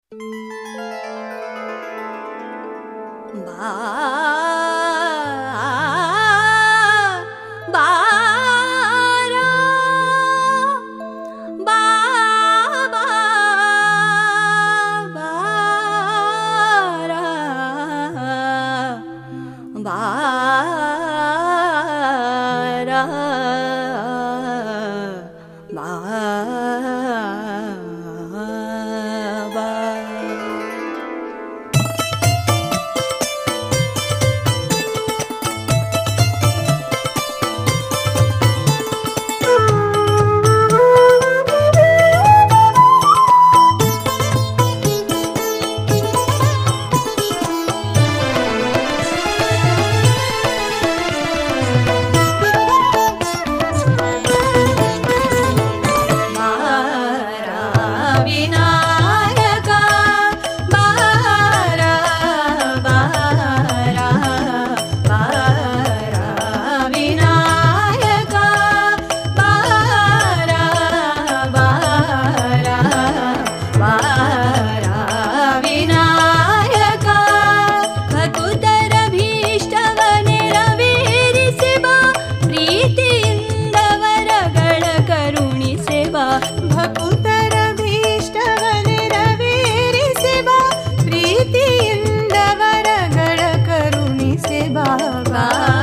Bhajans